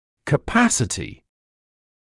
[kə’pæsətɪ][кэ’пэсэти]способность (делать что-либо); возможность